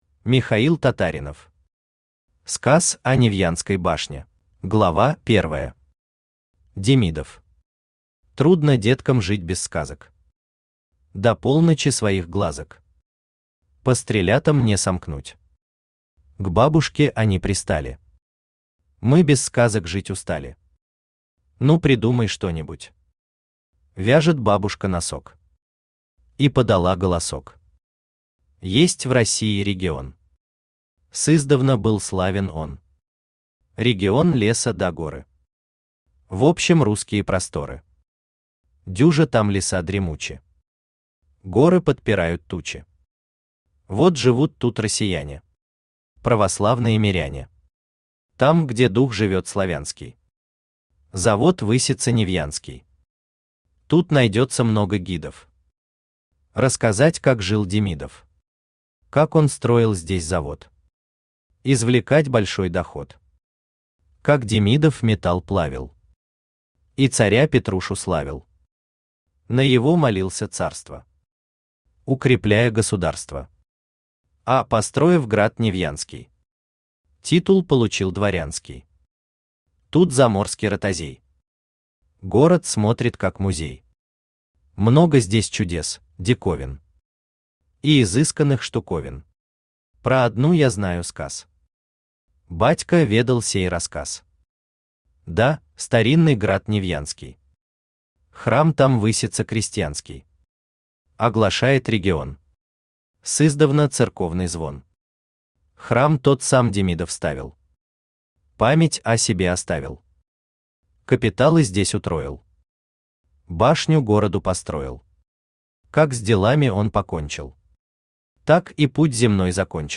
Аудиокнига Сказ о Невьянской башне | Библиотека аудиокниг